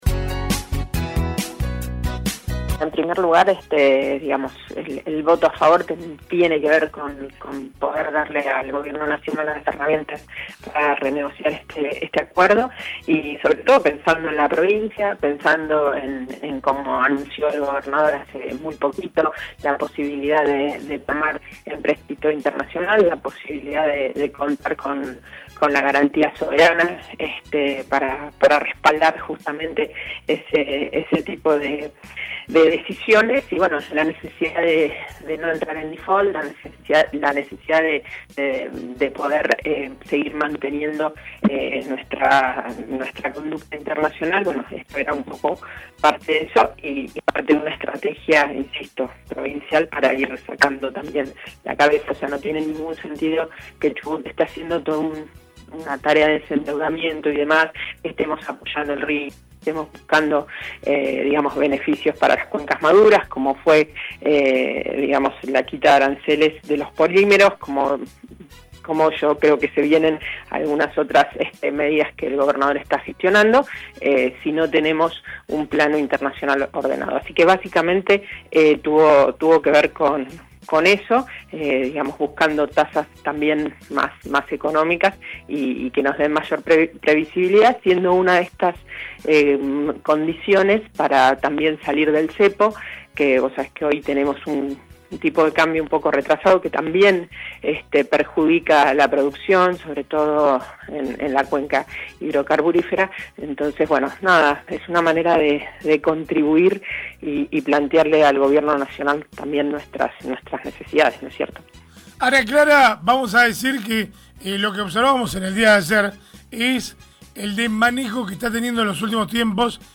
La diputada nacional del PRO, Ana Clara Romero, habló en Radiovisión sobre su voto en la sesión realizada el miércoles en el Congreso, en la que se aprobó el DNU que autoriza al Gobierno a firmar operaciones de crédito público contenidas en el Programa de Facilidades Extendidas con un plazo de amortización de 10 años .